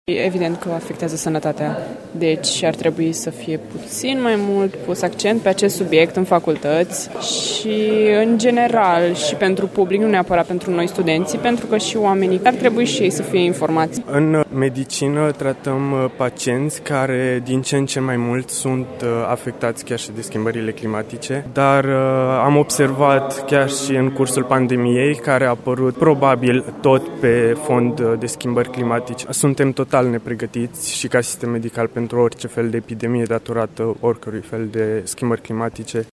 Studenții mediciniști din Târgu Mureș sunt dornici să afle mai multe pe tema influenței climei asupra stării de sănătate și recunosc că această abordare este încă în stadiu incipient la noi: